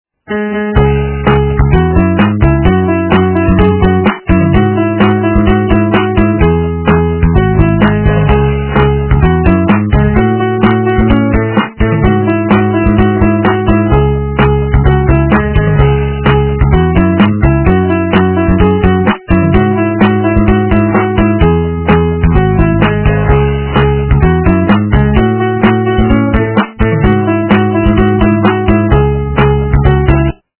- русская эстрада